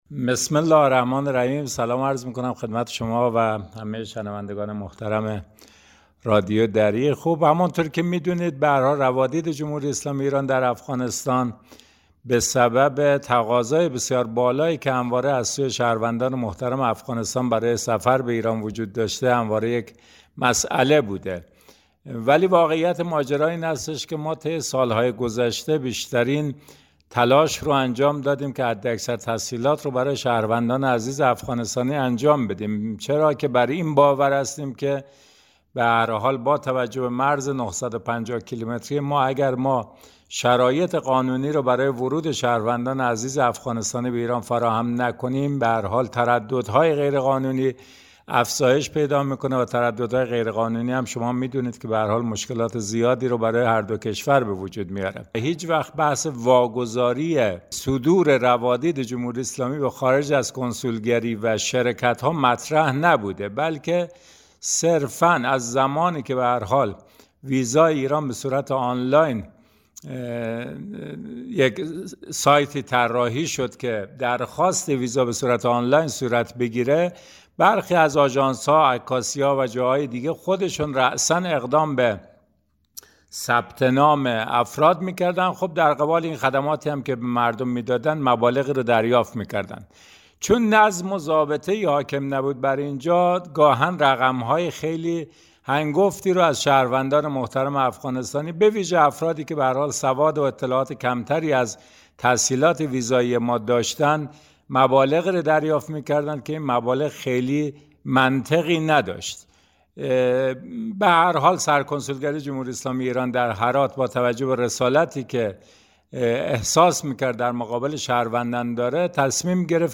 محمد صدیقی فر در گفت و گو با برنامه انعکاس رادیو دری در رابطه با برخی شایعات در مورد انحصاری شدن ثبت ویزای جمهوری اسلامی ایران توسط یک شرکت و اینکه سرکنسولگری ایران در هرات زمینه واگذاری ثبت ویزا را به شرکت های گردشگری طبق روال قبلی فراهم کرده گفت: هیچ واگذاری انجام نشده و برای جلوگیری از تجمع افراد در مقابل کنسولگری و ایجاد سهولت برای متقاضیان ویزا صرفا ارایه خدمات ویزای جمهوری اسلامی ایران آن هم در موضوع جهانگردی به جای اینکه در سرکنسولگری انجام شود در دفاتر کارگزاری تحت مدیریت سرکنسولگری در سه نقطه از شهر هرات و در دفاتری در مراکز ولایت های فراه، غور و بادغیس انجام می شود.